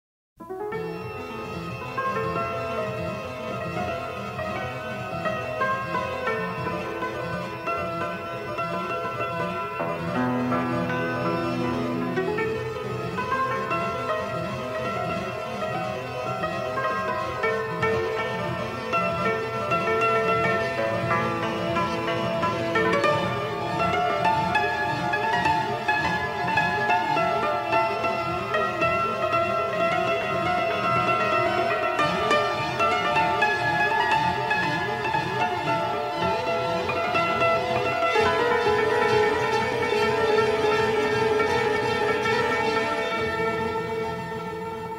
in their definitive stereo editions
Hungarian cymbalom instrument as a flamboyant color
original 1/4" stereo tape